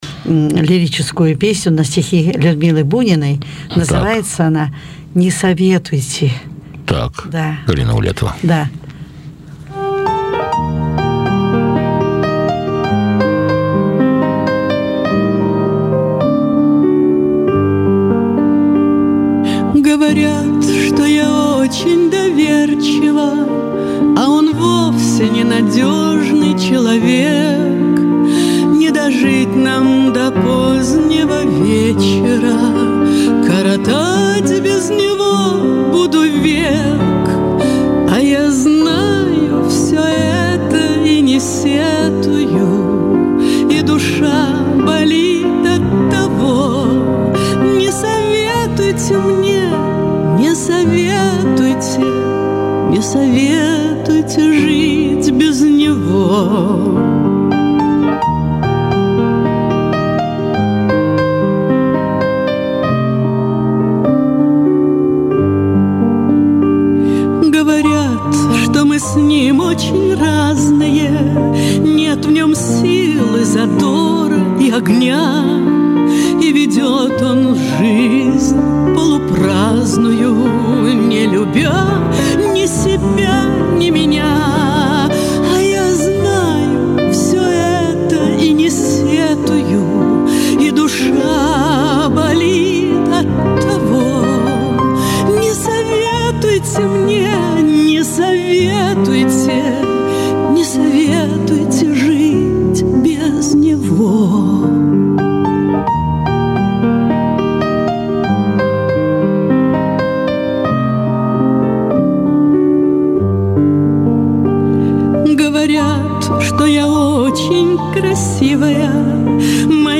Я составил этот сборник из песен,которые прозвучали на нескольких встречах Людмилы Алексеевны с известным радиожурналистом Борисом Алексеевым и сохранил коротенькие комментарии к каждой песне...